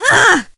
shelly_hurt_05.ogg